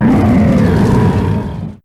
Grito de Incineroar.ogg
Grito_de_Incineroar.ogg.mp3